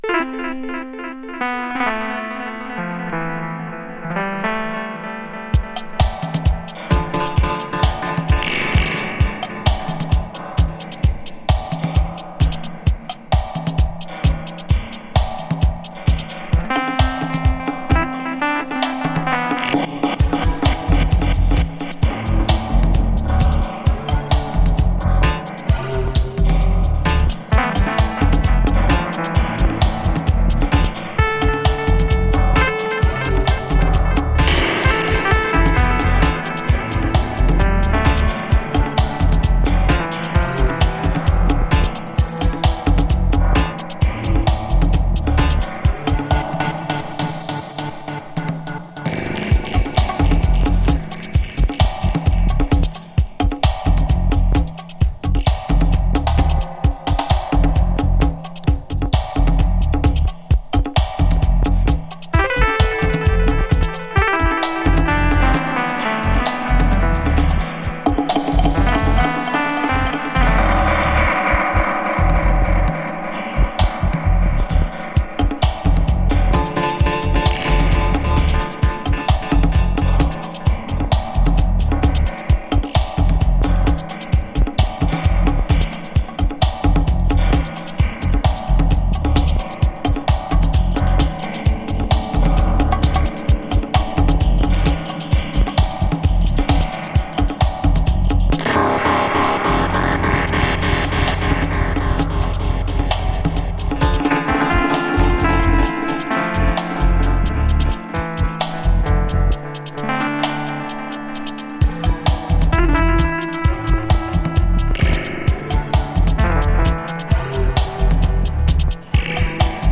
New Release Reggae / Dub Steppers